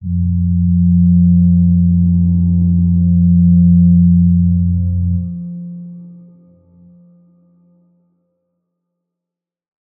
G_Crystal-F3-f.wav